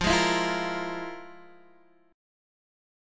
FmM11 chord